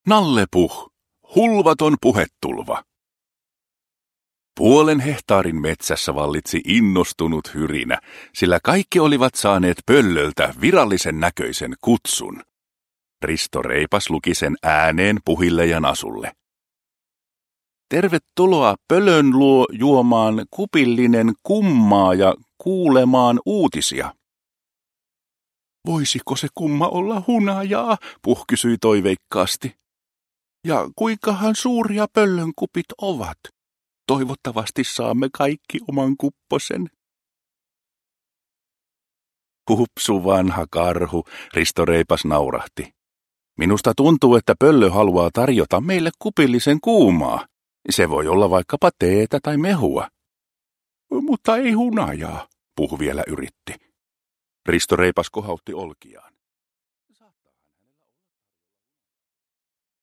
Nalle Puh. Hulvaton puhetulva – Ljudbok – Laddas ner